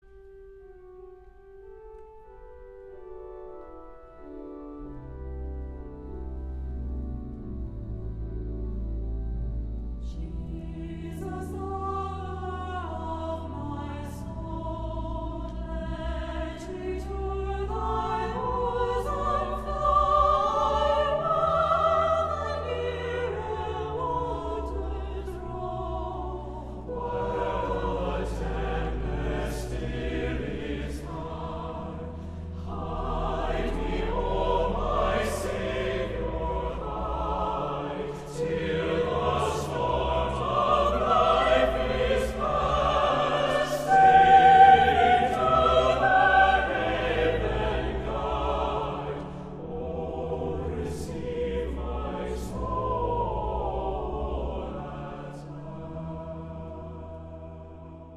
*A lyrical setting
*Folk-like melodies